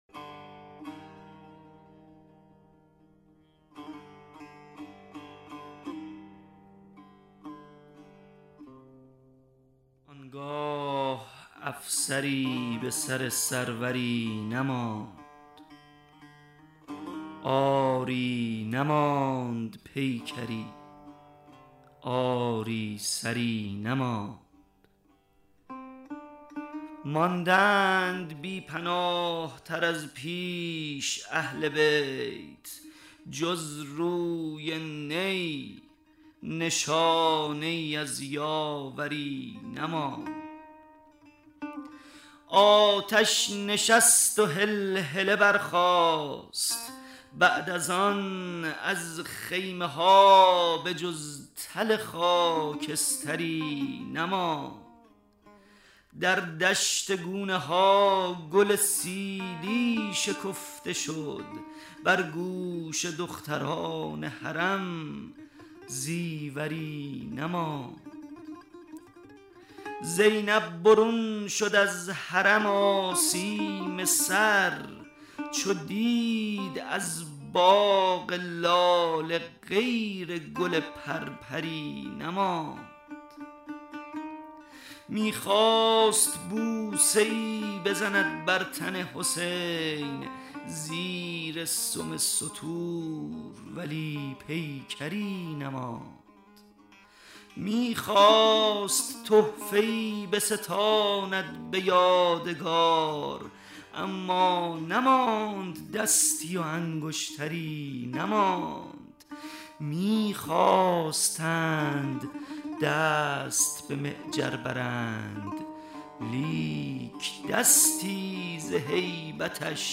بسته صوتی طریق اشک شعر خوانی شاعران آیینی (بخش سوم)
بسته صوتی طریق اشک مجموعه شعر خوانی تعدادی از شاعران اهل بیت است که به همت استودیو همنوا و با حمایت خانه ی موسیقی بسیج تهیه شده که در ایام اربعین از رادیو اربعین پخش خواهد شد.